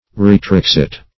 Retraxit \Re*trax"it\, n. [L., (he) has withdrawn. See